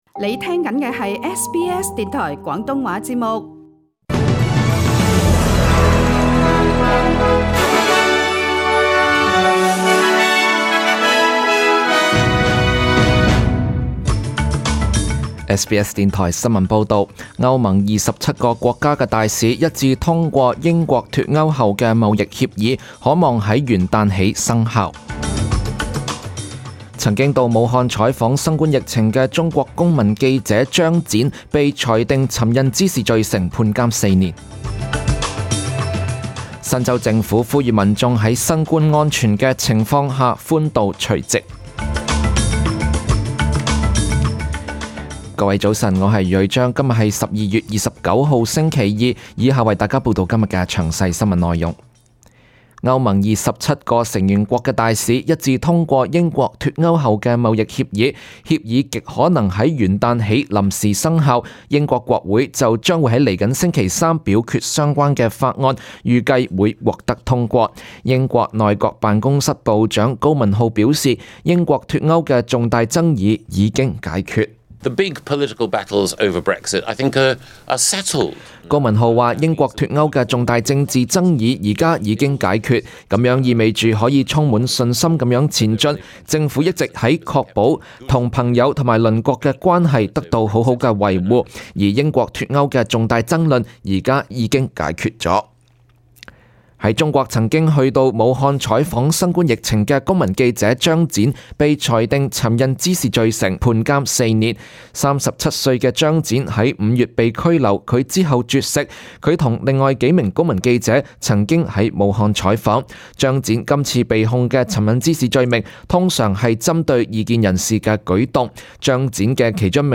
SBS中文新闻 （十二月二十九日）